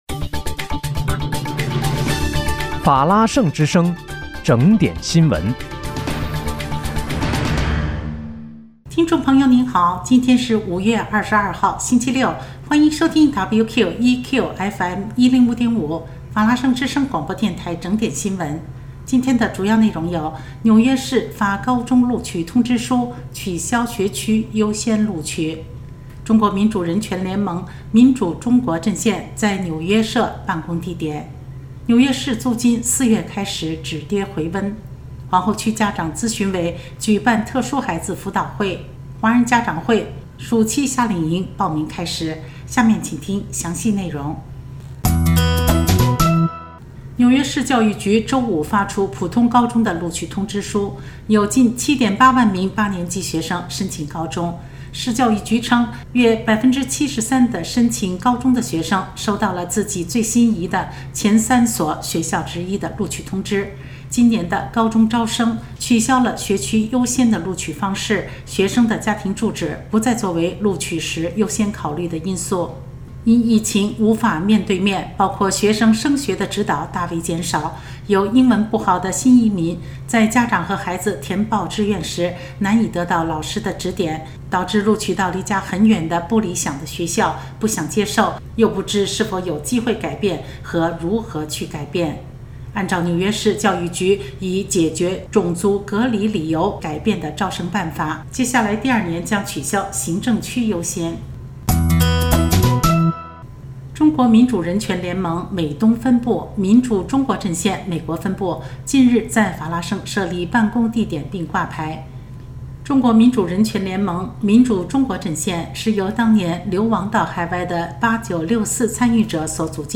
5月22日（星期六）纽约整点新闻
听众朋友您好！今天是5月22号，星期六，欢迎收听WQEQFM105.5法拉盛之声广播电台整点新闻。